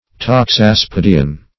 Search Result for " taxaspidean" : The Collaborative International Dictionary of English v.0.48: Taxaspidean \Tax`as*pid"e*an\, a. [Gr.